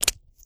STEPS Pudle, Walk 26.wav